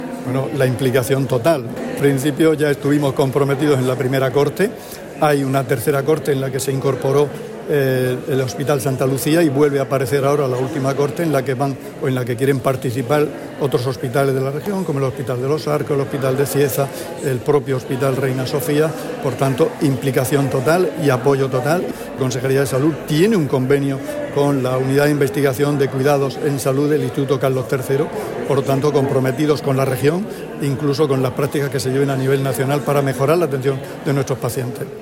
Sonido: Declaraciones del consejero de Salud, Juan José Pedreño, sobre la jornada de Cuidados de la Salud Basados en la Evidencia